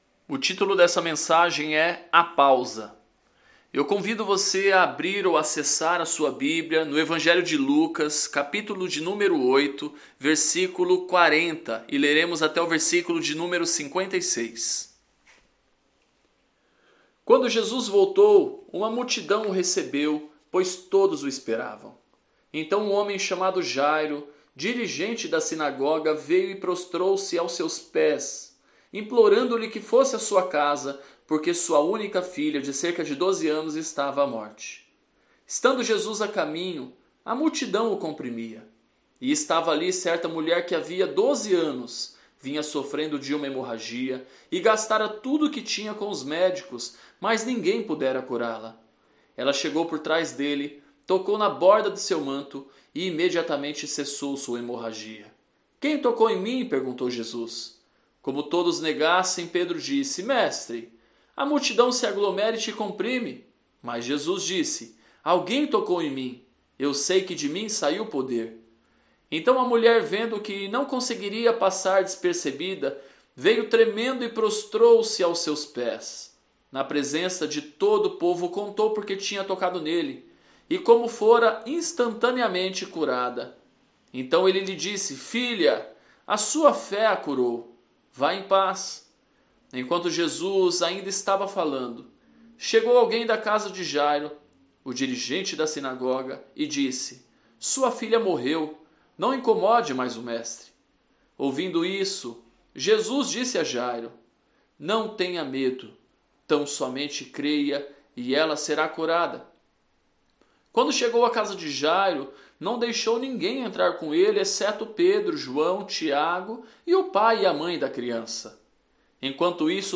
Este episódio está com algumas mudanças de áudio devido a problemas técnicos.